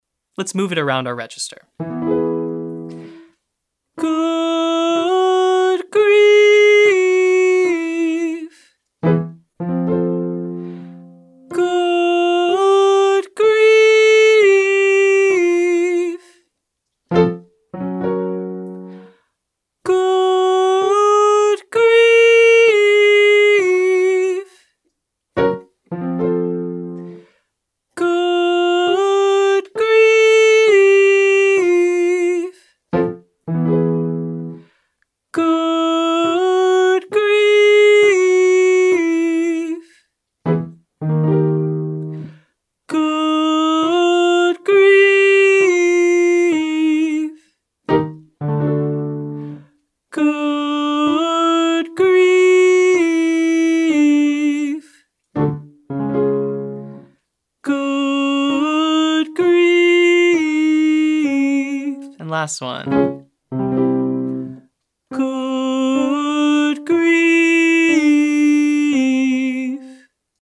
Short Daily Warmup